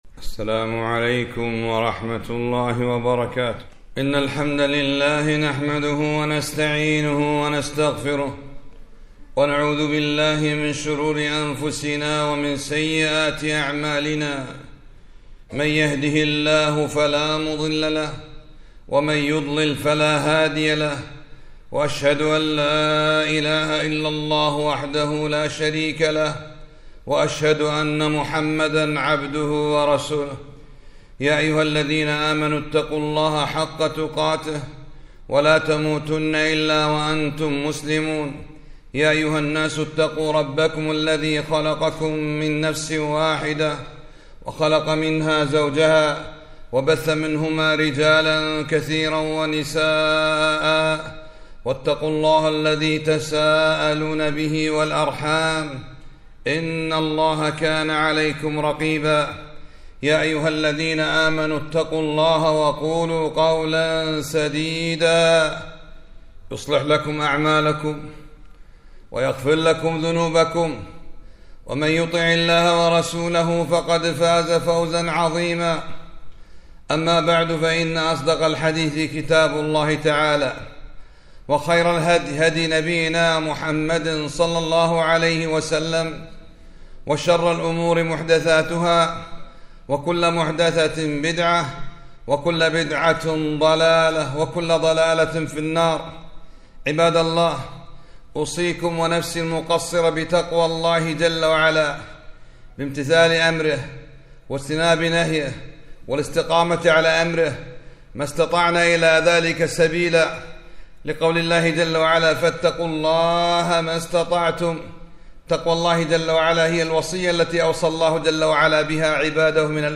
خطبة - تفسير سورة الإخلاص